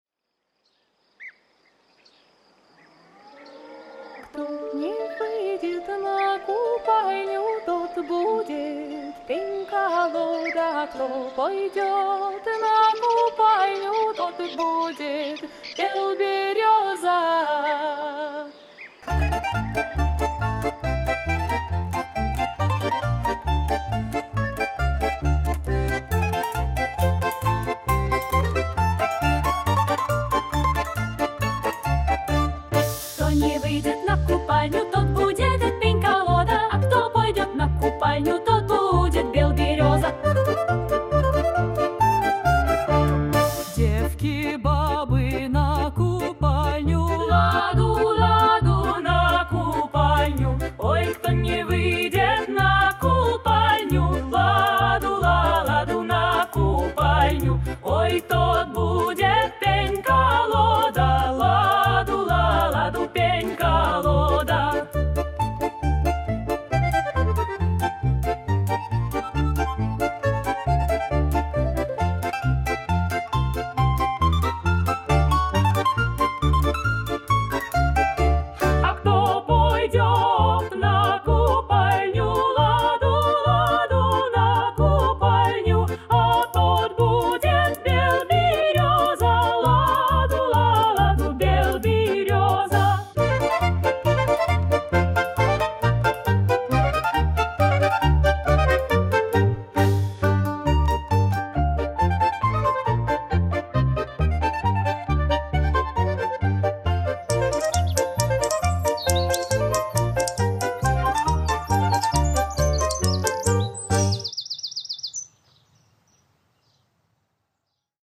Народные песни праздника Ивана Купала.